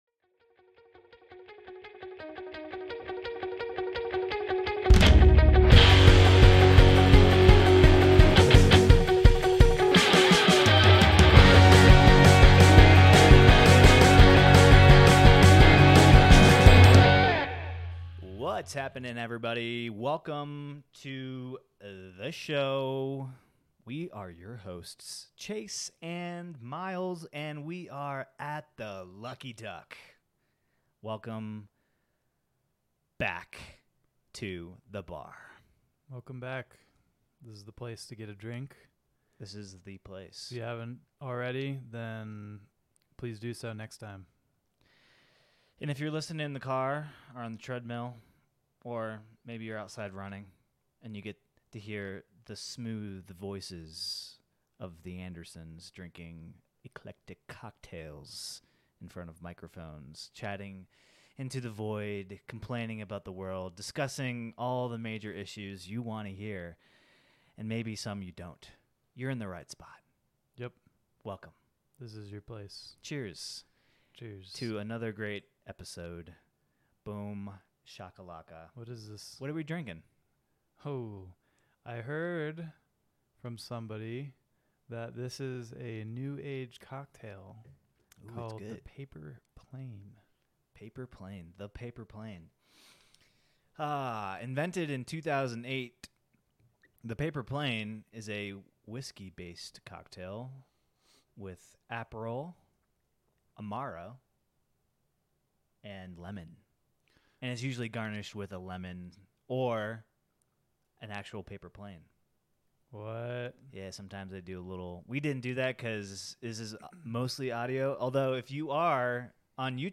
share conversation over cocktails.